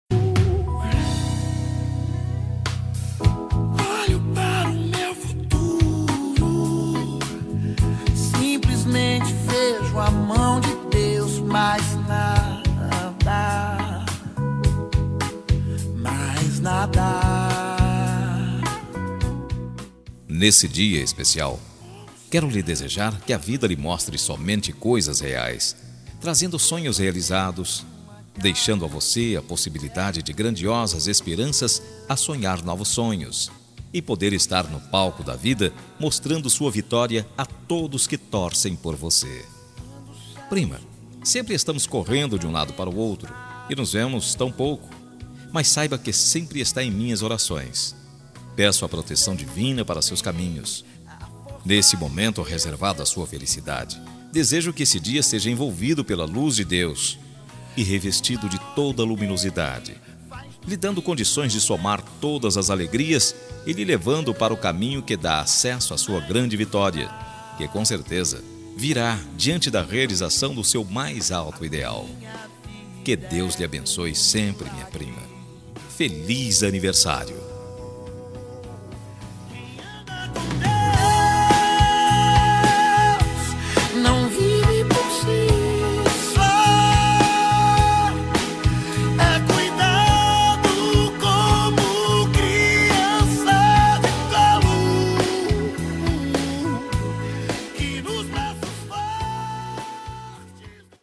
Voz Masculina